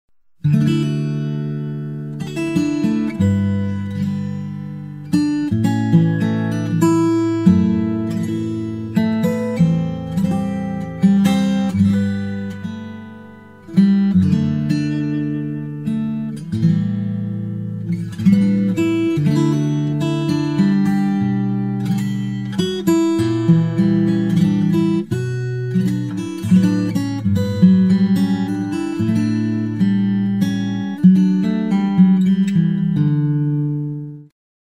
This is the full album version